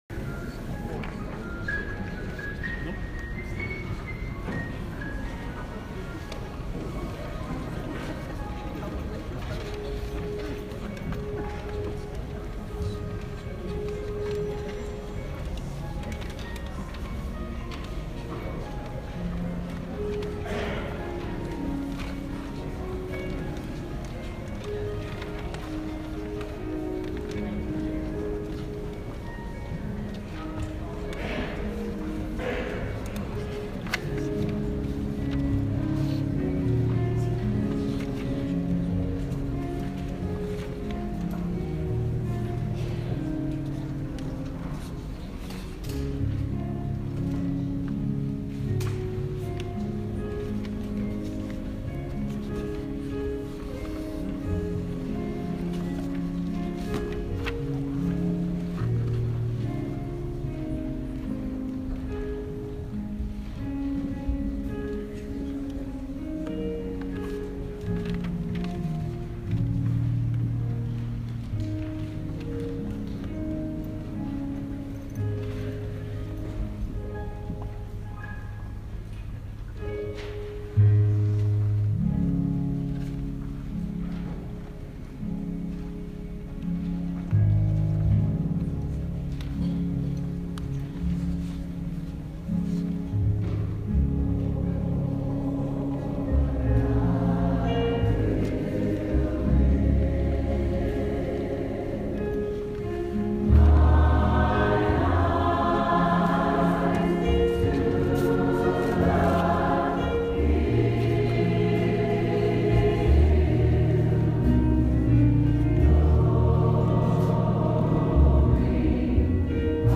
Ecumenical Service of Reconciliation – Presiding Bp. Michael Curry, speaker
A wonderful blend of liturgical and free church elements, plus a powerful message about reconciliation as a Gospel imperative. Plus it was wonderful to have representatives from multiple Christian traditions participating. The full service is recorded (with a brief pause while personal prayer groups were transpiring). Please forgive any sound quality issues or extraneous noises or comments in the congregation that might distract.